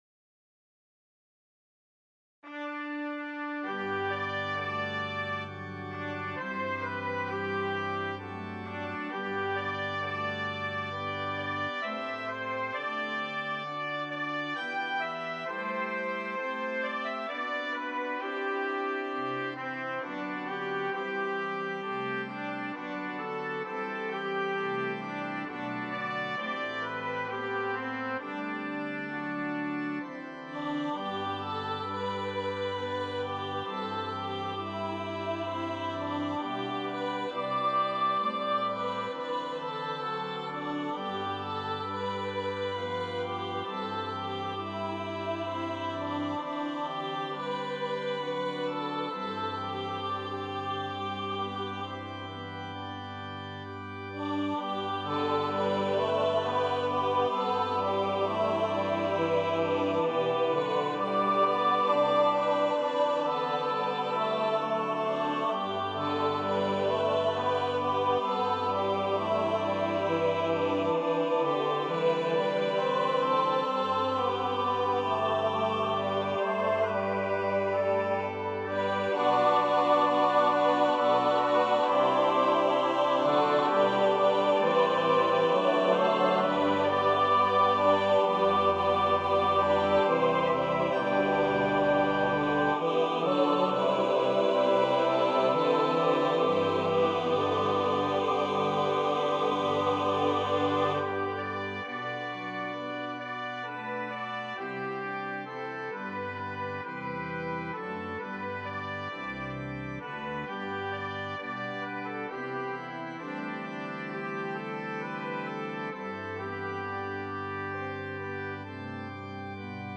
• Music Type: Choral
• Voicing: SATB
A solo instrument provides a colorful counterpoint.